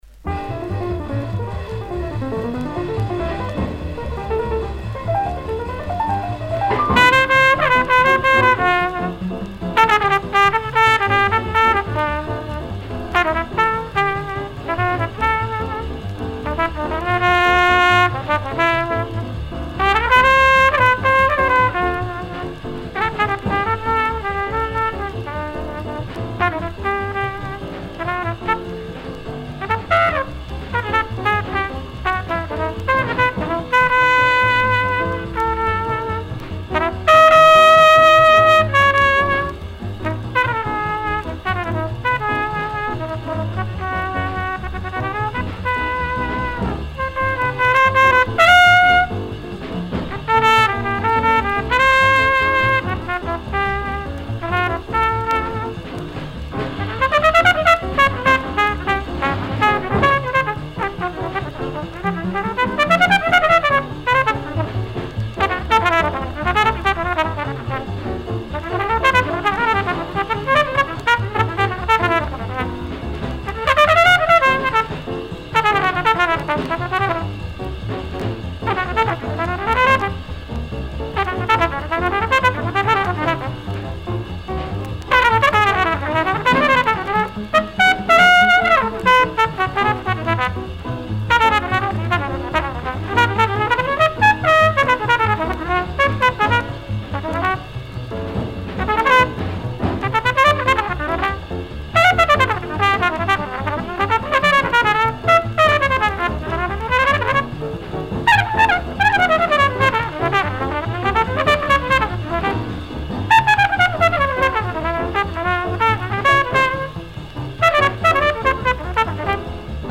Recorded October 15, 1953 in Paris